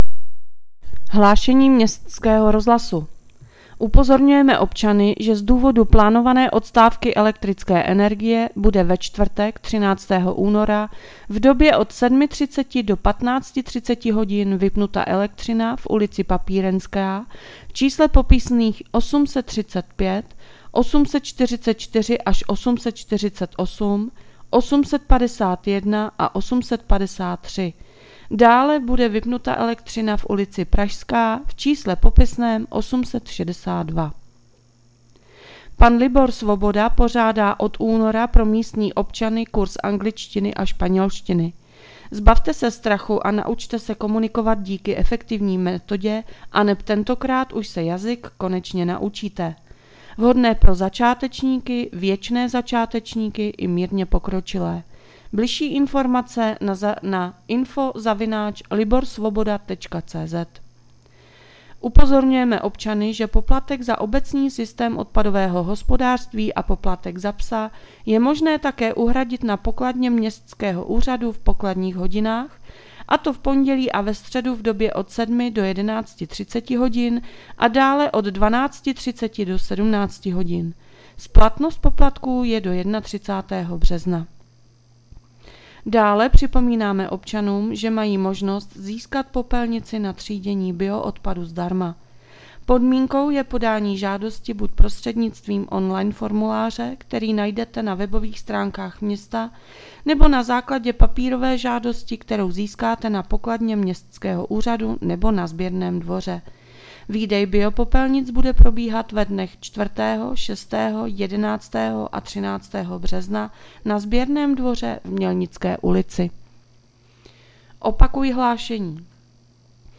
Hlášení městského rozhlasu 12.2.2025